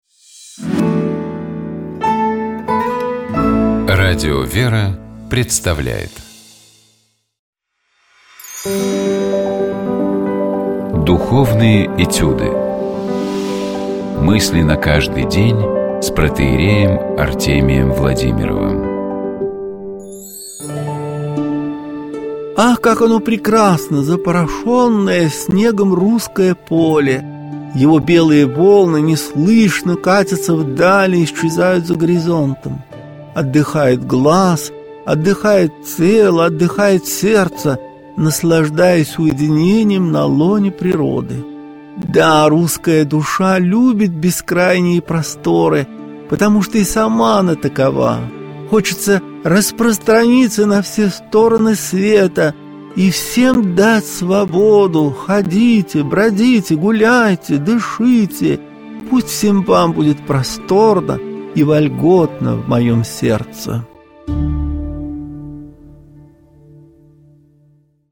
У нас в гостях была вице-губернатор Архангельской области, член организационного комитета по подготовке к празднованию столетия со дня рождения писателя Федора Абрамова Елена Кутукова.